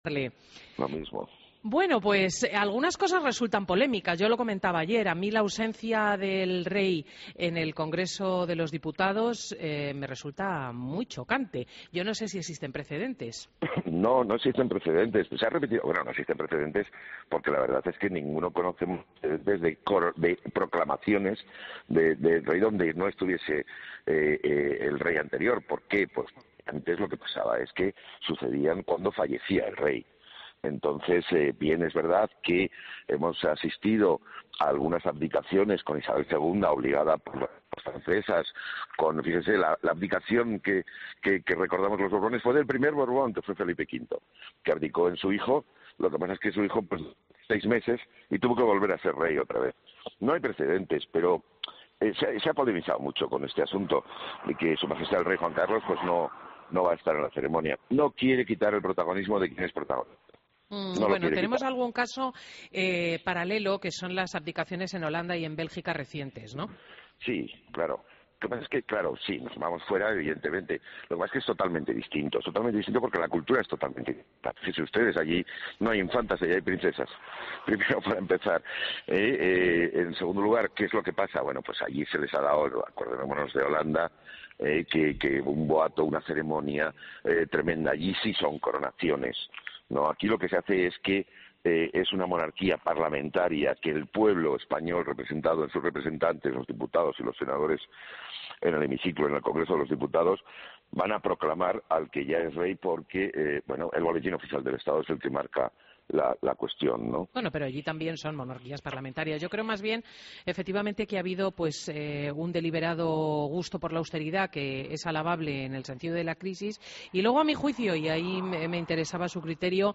Entrevistas en Fin de Semana Entrevista